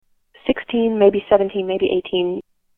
Female voice